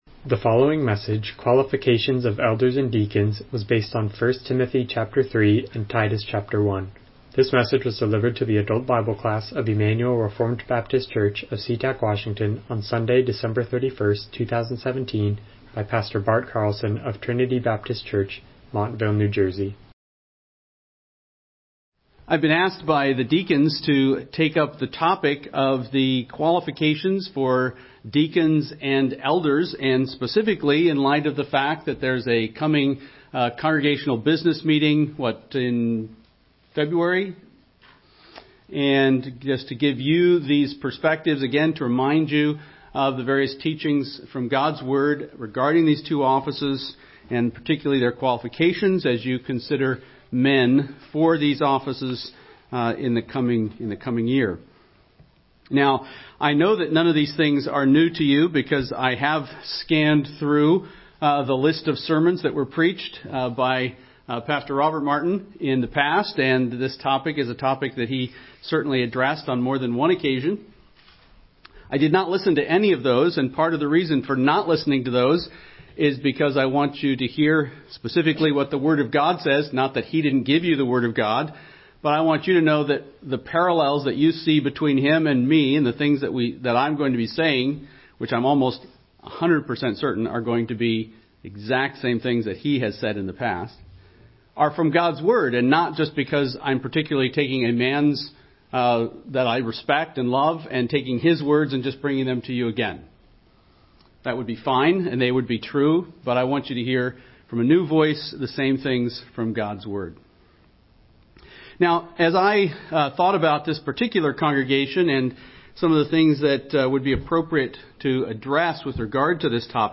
Passage: 1 Timothy 3:1-16, Titus 1:1-16 Service Type: Sunday School